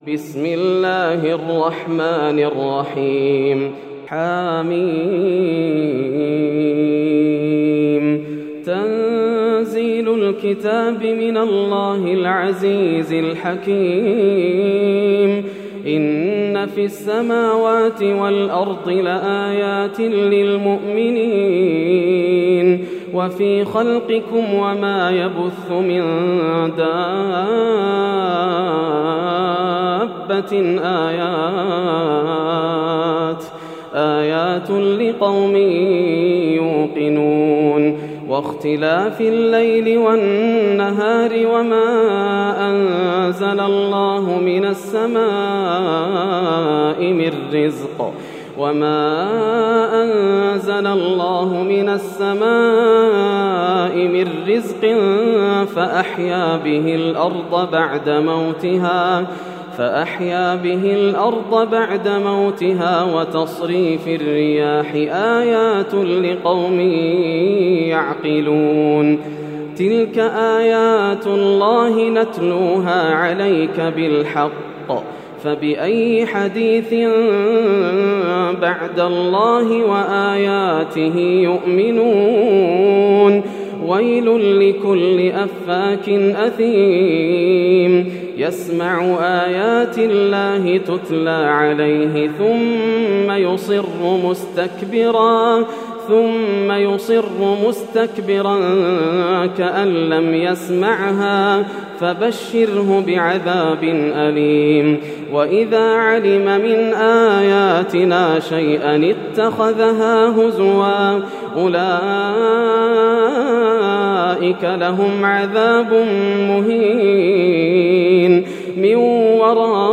سورة الجاثية > السور المكتملة > رمضان 1431هـ > التراويح - تلاوات ياسر الدوسري